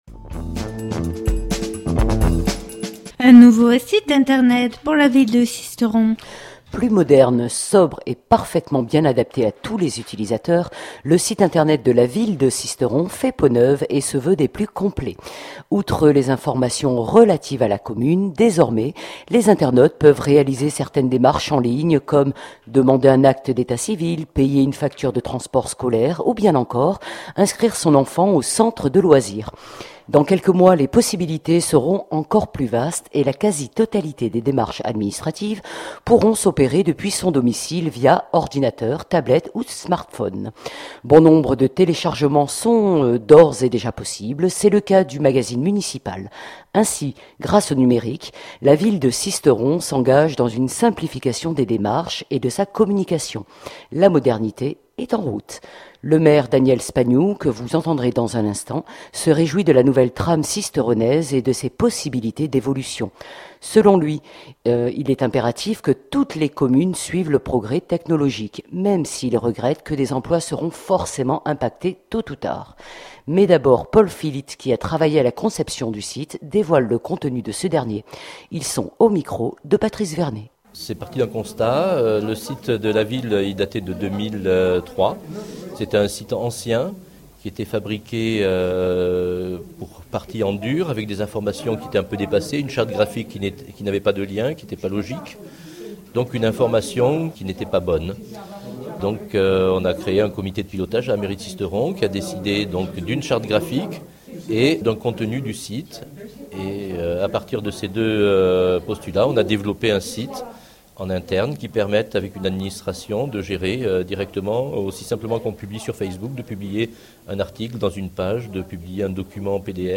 Le Maire Daniel Spagnou, que vous entendrez dans un instant, se réjouit de la nouvelle trame sisteronaise et de ses possibilités d’évolution. Selon-lui il est impératif que toutes les communes suivent le progrès technologique, même s’il regrette que des emplois seront forcément impactés tôt ou tard.